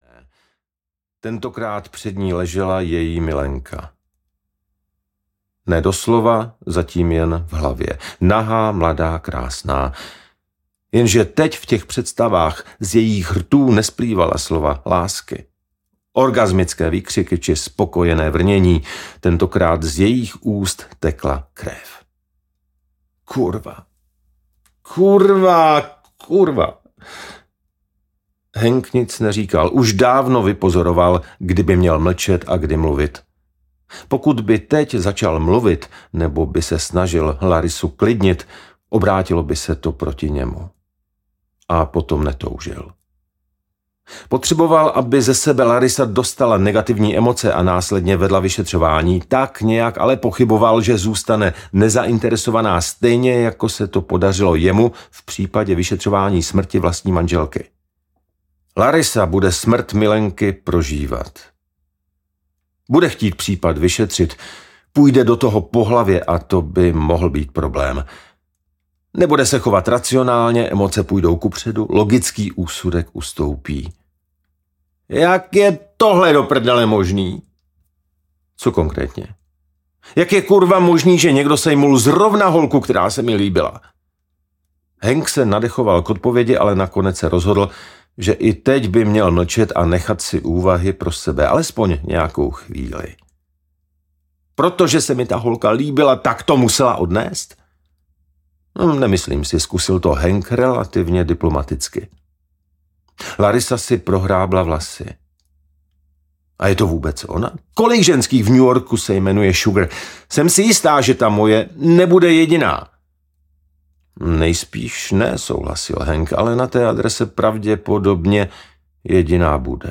Farma zrůd audiokniha
Ukázka z knihy